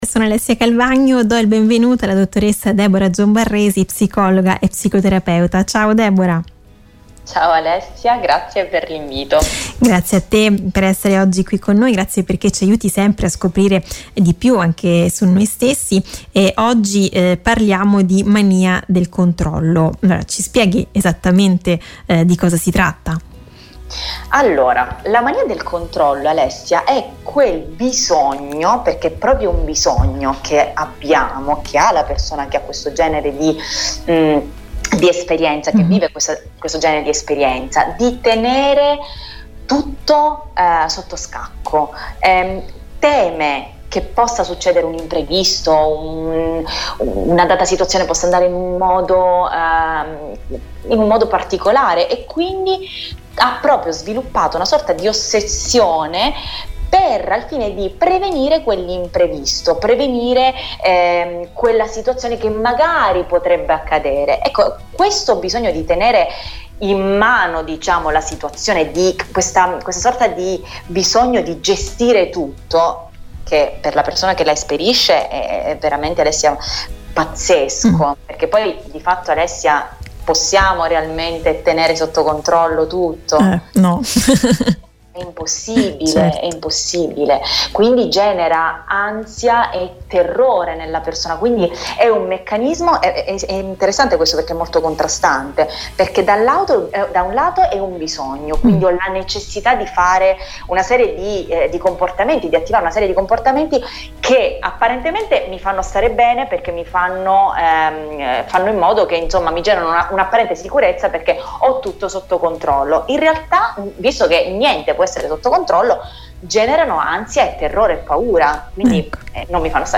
Intervista a